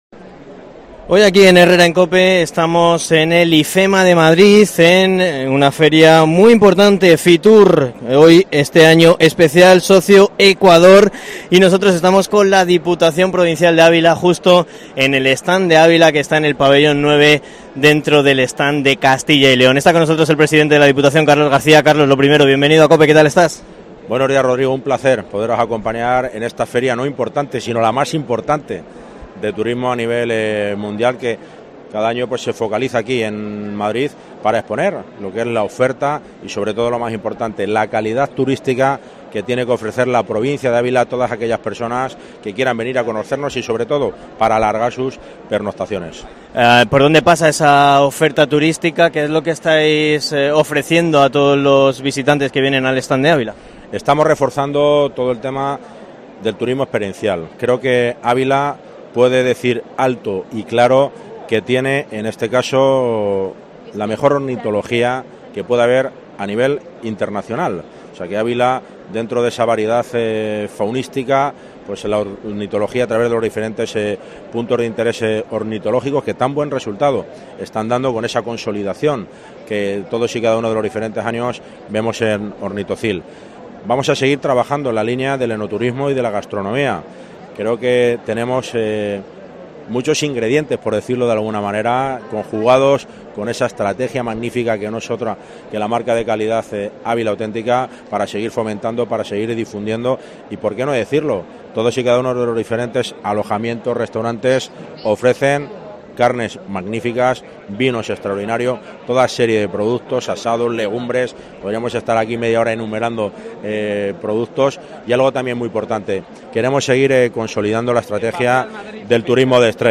ENTREVISTA / El presidente de la Diputación de Ávila, Carlos García, en FITUR
El presidente de la Diputación de Ávila, Carlos García, en el programa especial de COPE Ávila y la Diputación desde FITUR